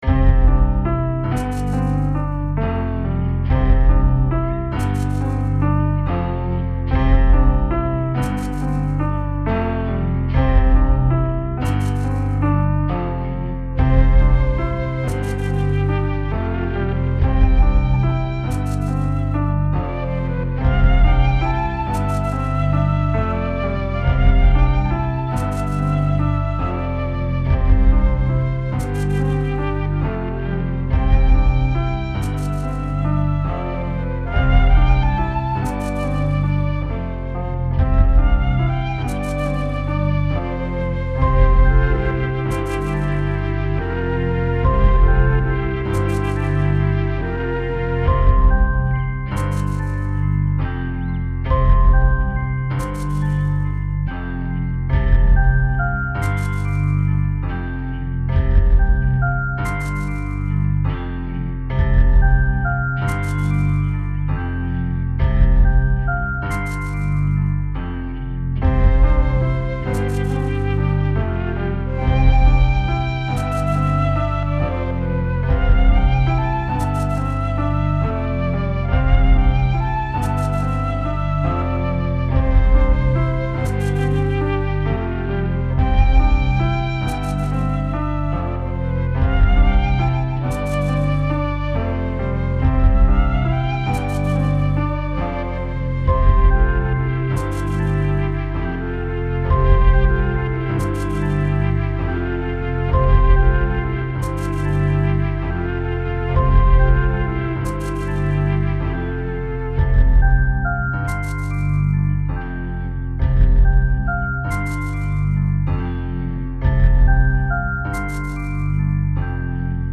This track of music represents a tranquil place. The hero finds a location that is magical and magestic so that it makes the hero forget about the tribulatiion that awaits outside.Â  IÂ  endeavored to record this track with real instruments to give it some life.
Instead of the epic psuedo-symphonic music or else the J-pop/ new age music that is typically featured in an RPG, I thought it would be interesting to have an RPG with kind-of a late 60s/early 70s style psychodelic acid-fusion soundtrack.
The drums and a bass line are still sequenced.
I ran it through my Pod Xt guitar pedal and came-up with a retro sounding effect with a limited frequency response and a detuning effect.
The lead is a mellotron clarinet.
I added a B section in a relative minor.
The track ends abruptly because it is meant to loop.
Filed under: Instrumental Information Remix | Comments (9)